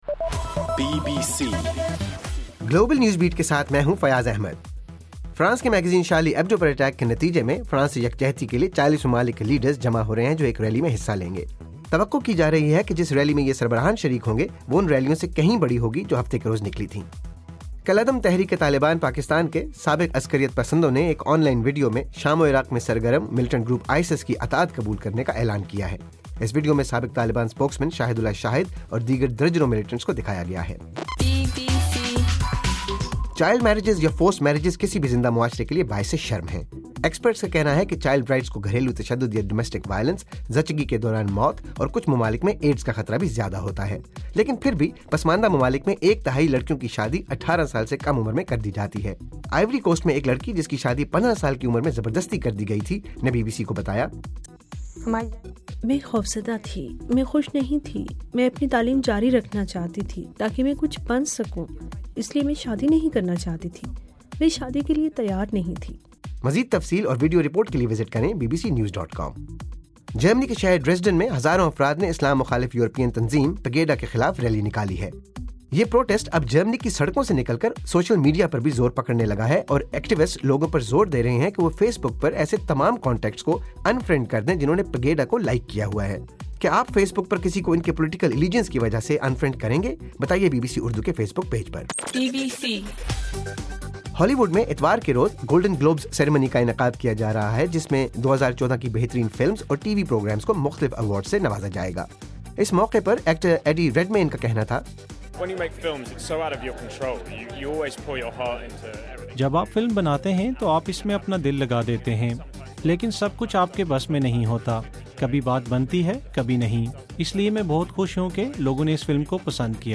جنوری 11: رات 9 بجے کا گلوبل نیوز بیٹ بُلیٹن